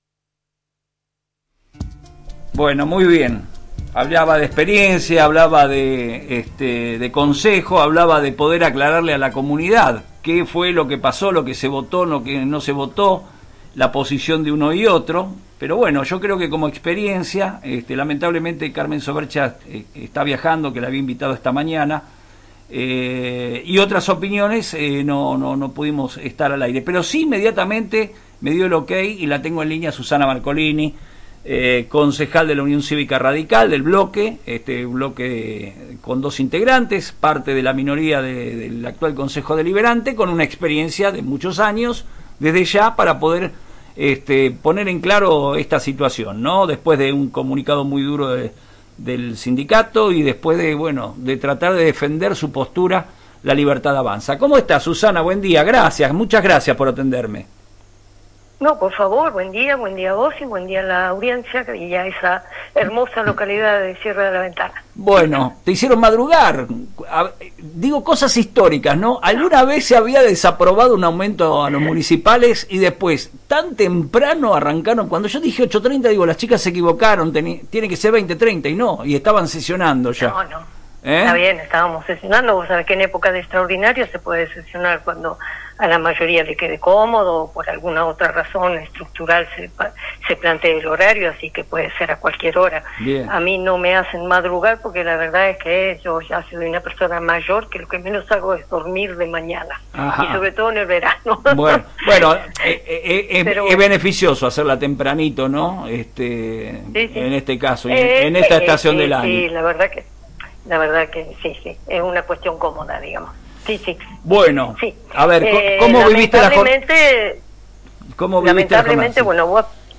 La concejal de la UCR Susana Marcolini, analizó en el programa «Un Mate y un Café» el voto negativo del bloque libertario ante el aumento del 3% para la planta municipal. Si bien coincidió con algunos argumentos sobre la necesidad de restringir el gasto político, explicó por qué legalmente el cuerpo deliberativo no puede fragmentar las ordenanzas salariales ni reasignar partidas del Ejecutivo.